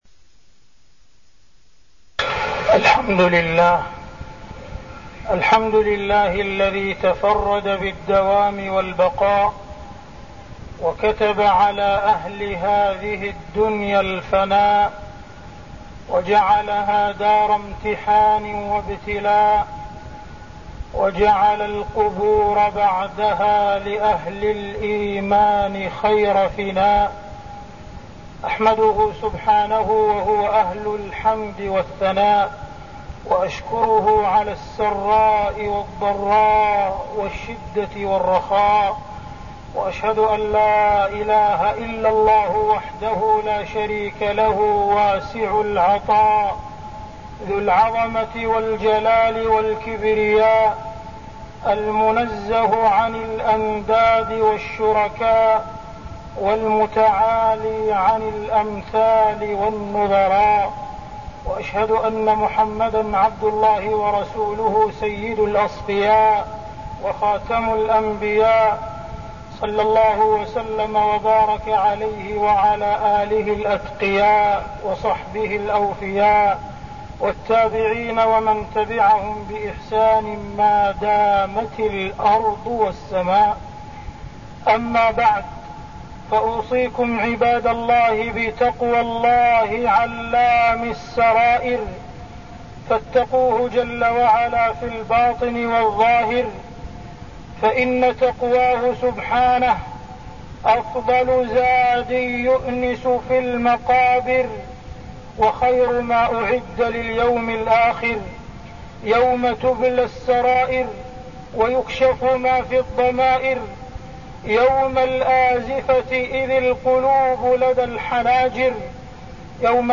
تاريخ النشر ١٣ رجب ١٤١٢ هـ المكان: المسجد الحرام الشيخ: معالي الشيخ أ.د. عبدالرحمن بن عبدالعزيز السديس معالي الشيخ أ.د. عبدالرحمن بن عبدالعزيز السديس الإكثار من تذكر المصير المحتوم